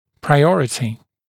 [praɪ’ɔrətɪ][прай’орэти]приоритет, первенство, преимущество